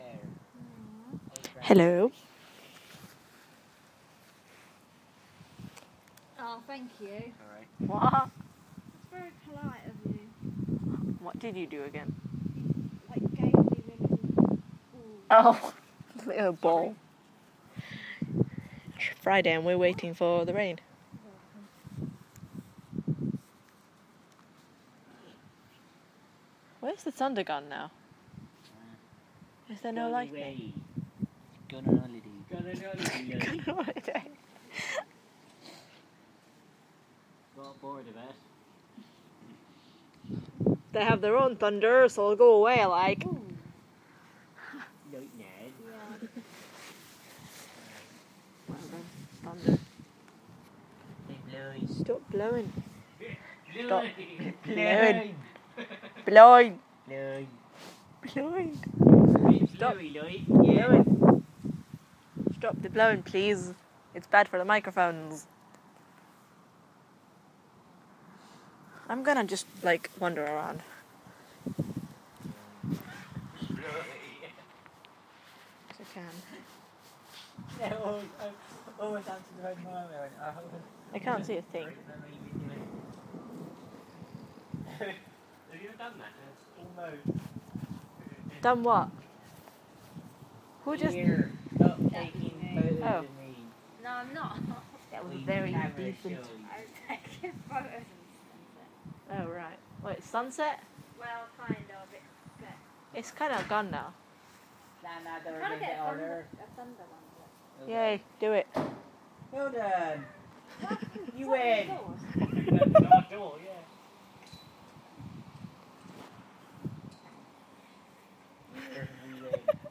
Share Facebook X Next Another thunderstorm. Partly from outside, partly from inside